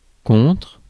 Ääntäminen
Tuntematon aksentti: IPA: /kɔ̃tʁ/